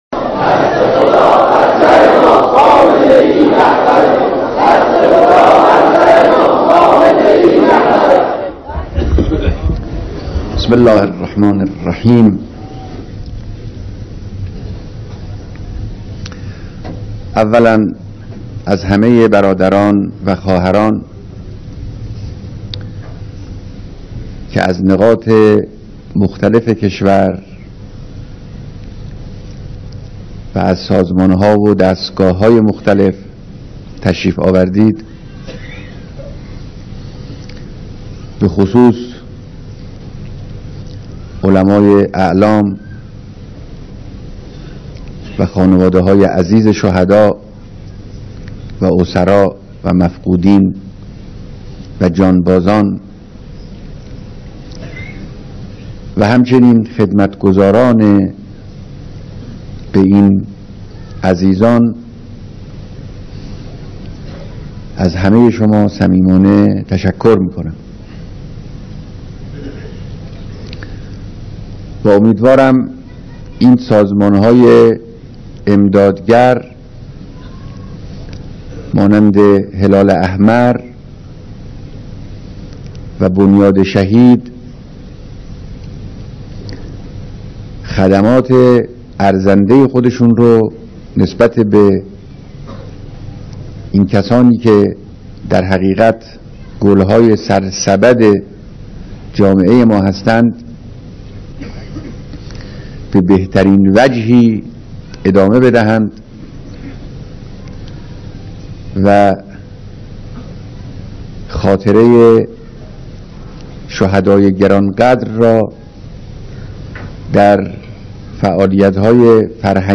بیانات معظم له در دیدار اعضاء جمعیت هلال احمر، مسؤولین بنیاد شهید و اقشار مختلف مردم